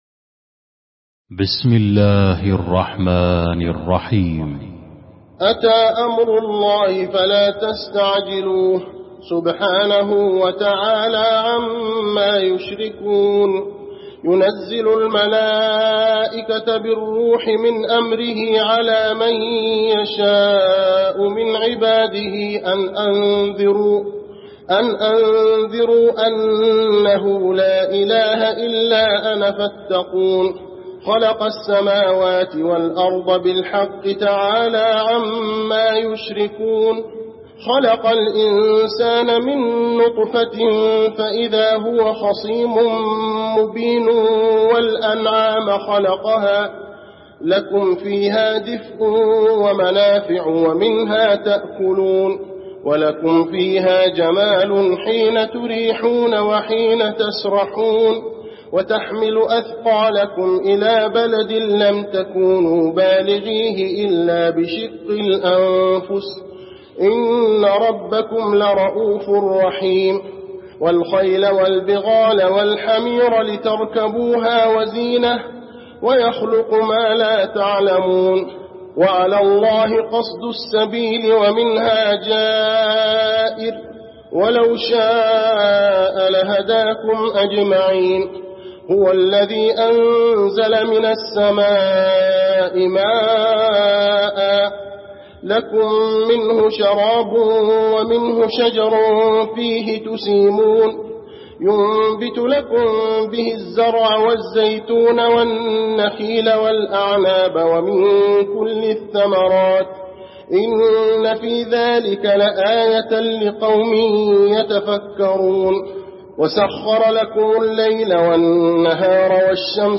المكان: المسجد النبوي النحل